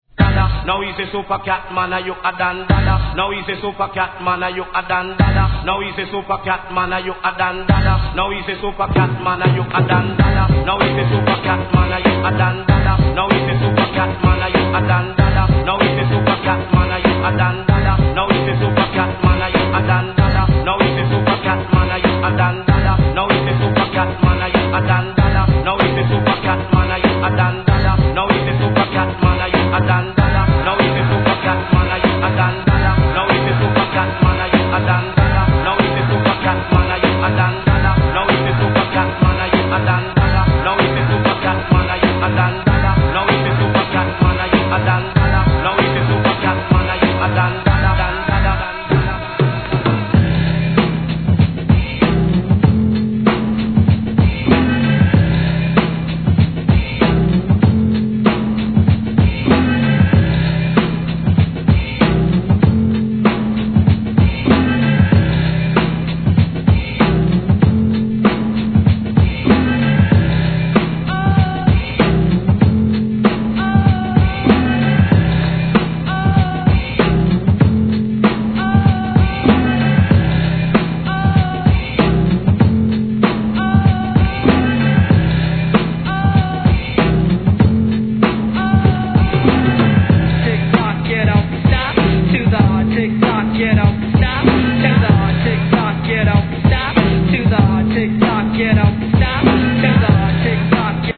(B-1の冒頭が傷無いようなのですがLOOPします)
HIP HOP/R&B